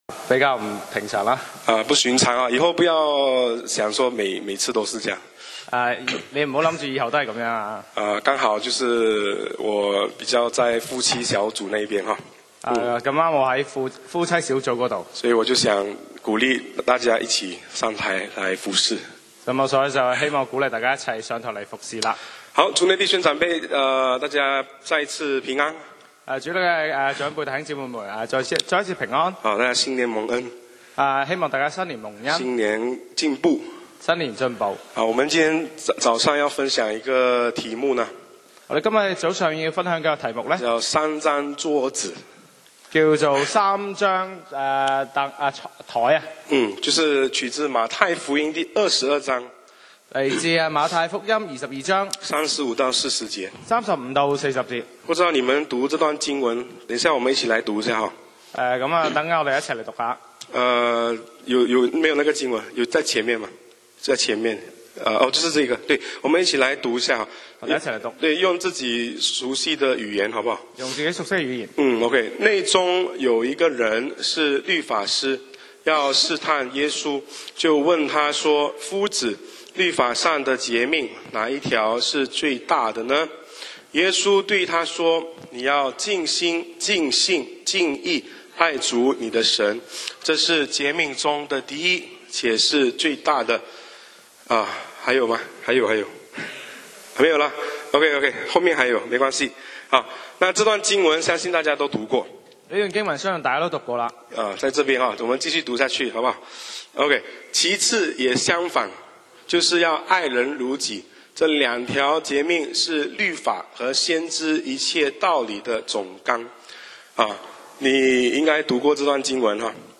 講道 Sermon 題目 Topic：三个桌子 經文 Verses：马太福音 22:35-40 35内中有一个人是律法师，要试探耶稣，就问他说，36夫子，律法上的诫命，那一条是最大的呢？